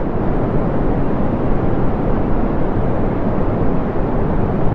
rocket2.ogg